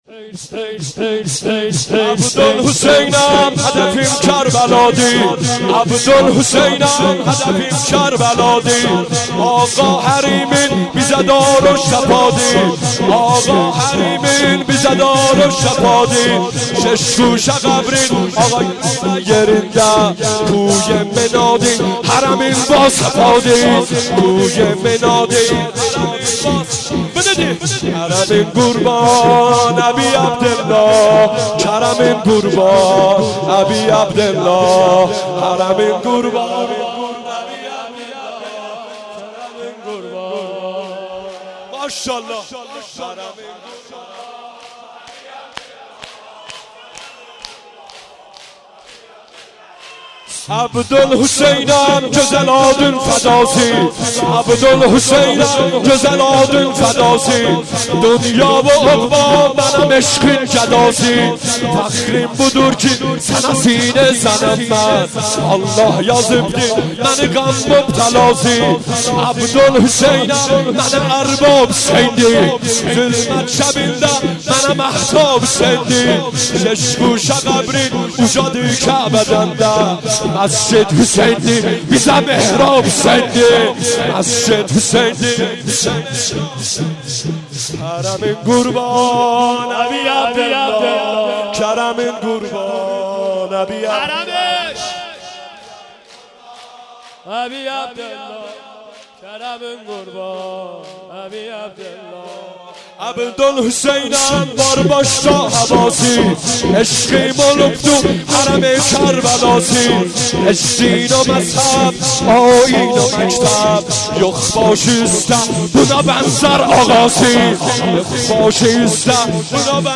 سینه زنی شور